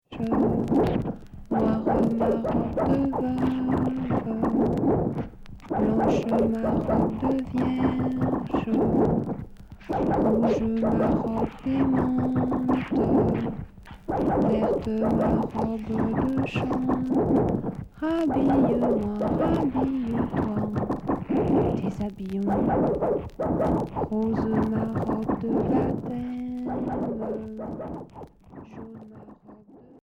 Experimental bruitiste indus